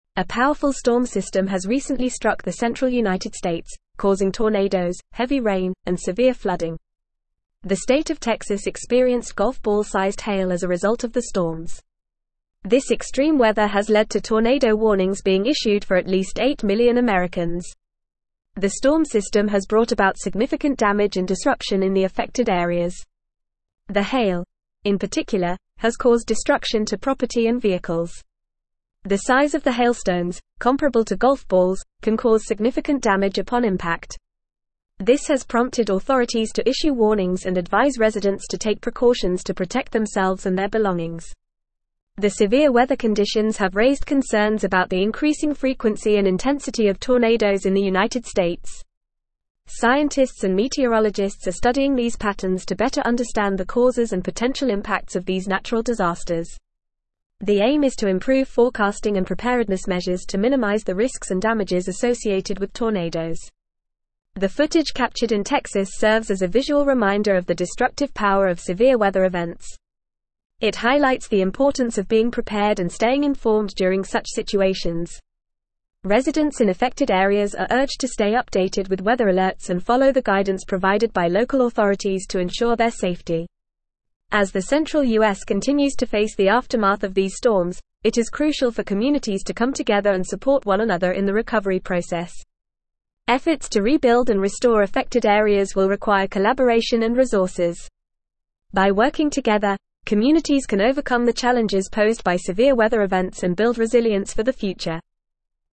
Fast
English-Newsroom-Advanced-FAST-Reading-Severe-Storm-System-Causes-Tornadoes-Flooding-and-Hail.mp3